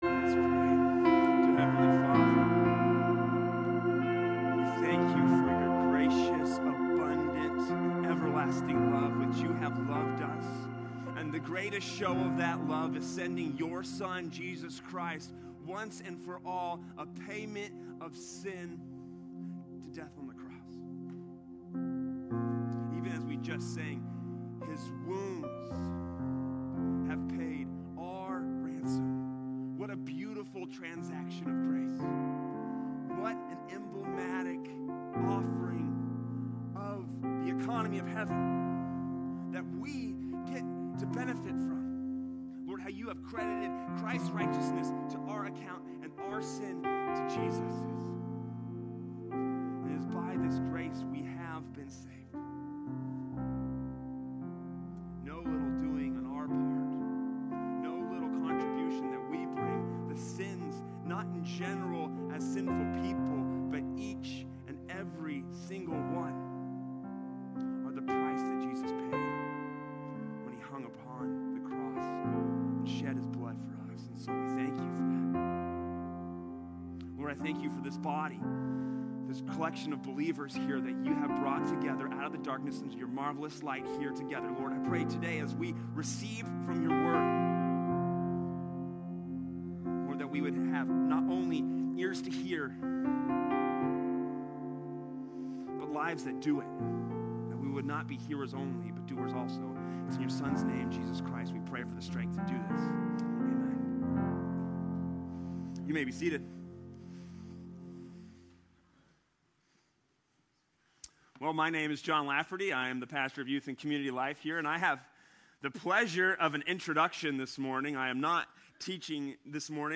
Sermon Archive | Avondale Bible Church
From Series: "Guest Speakers"